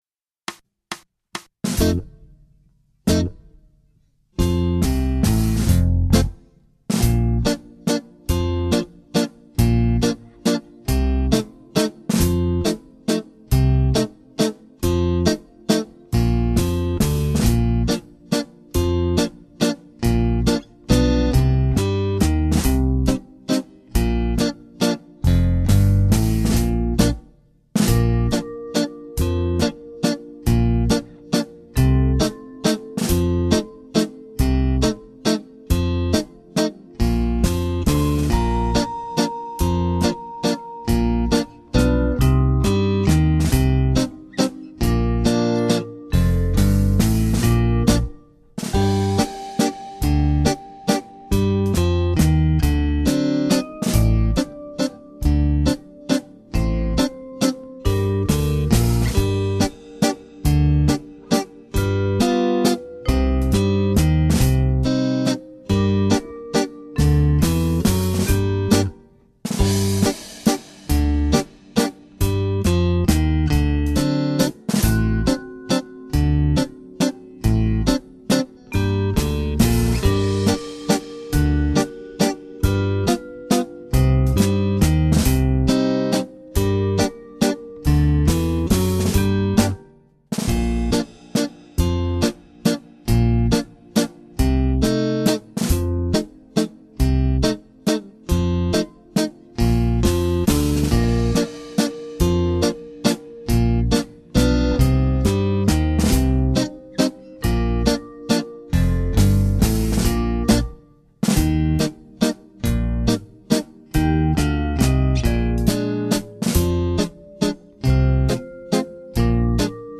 Genere: Mazurka sax